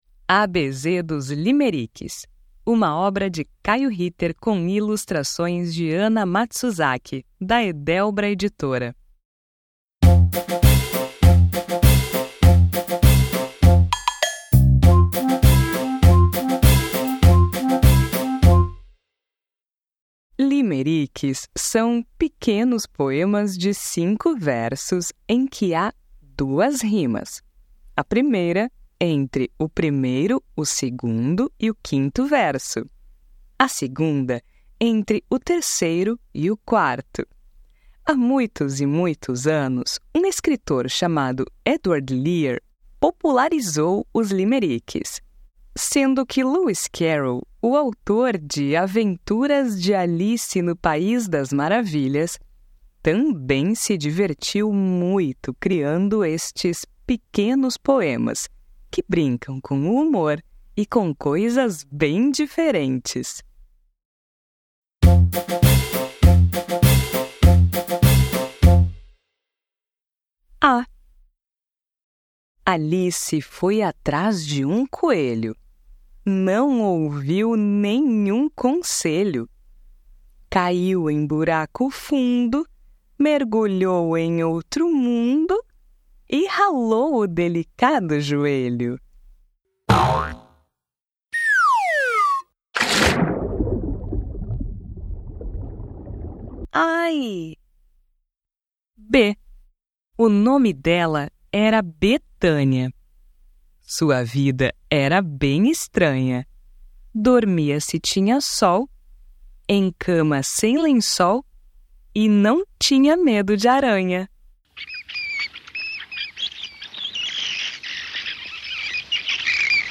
• Gênero: Poema
Amostra do audiolivro